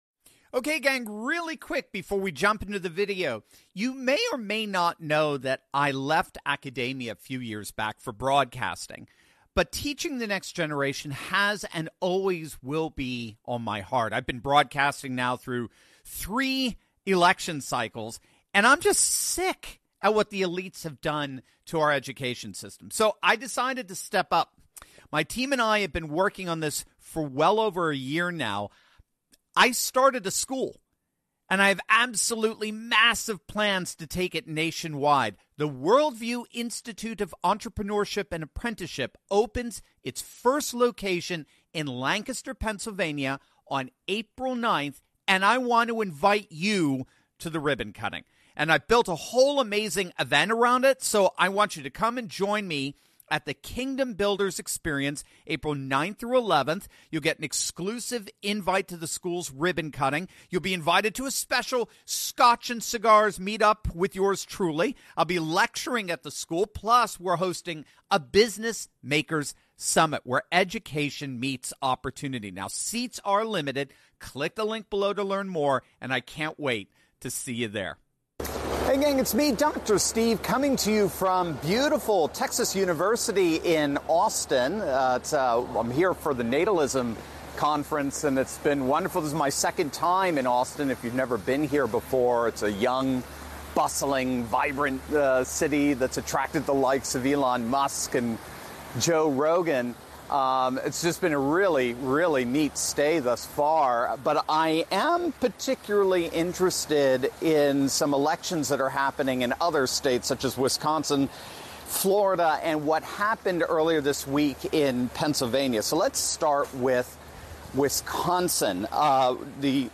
A former teacher, now a broadcaster, has opened a school and wants to expand it across the country. He talks about elections in Wisconsin and Florida, stressing how crucial it is for people to vote. He shares how a Democrat won in Pennsylvania because many Republicans didn't vote.